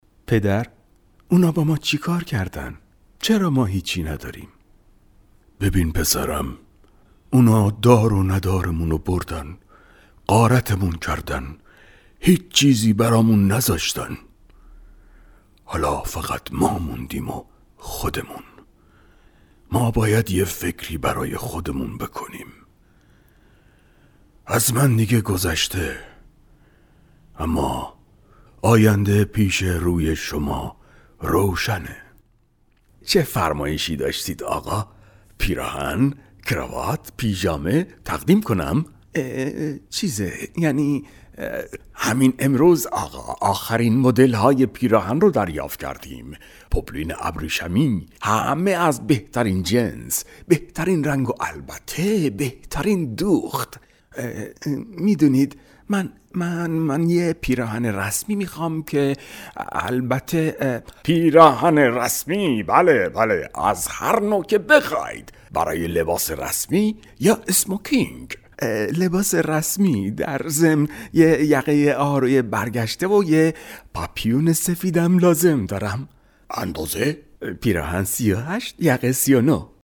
Male
Adult
Voice Acting